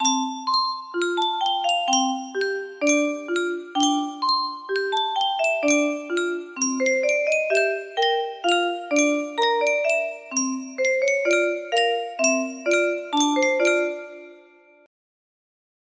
Yunsheng Music Box - Hail University of Maryland 1037 music box melody
Full range 60